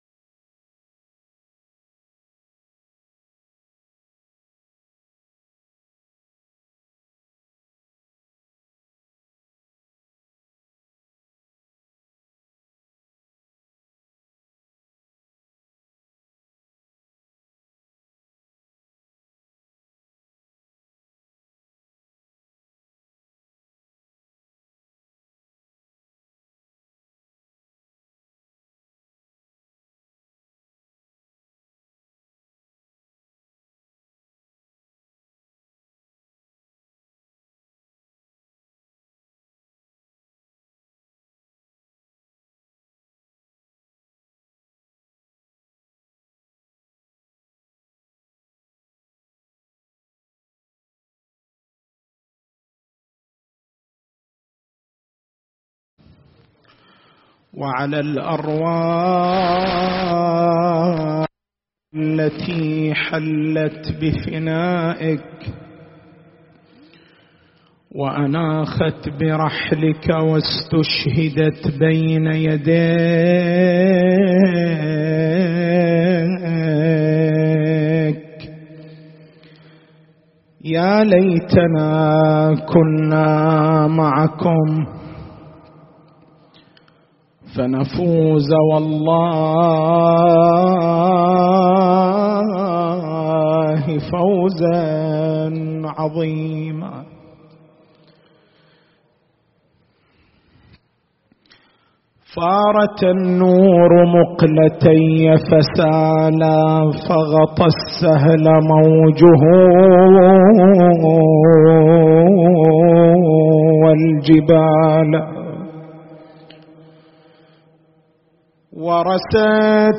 حسينية بن جمعة بالكويكب حسينية عمارة بالربيعية حسينية الزين بالقديح